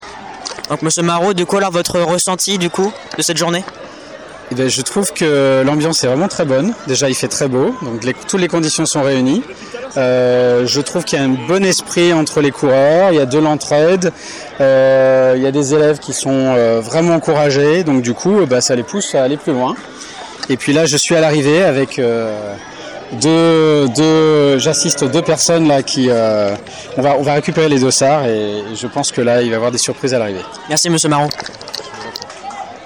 Interview
Le cross du collège 2025 !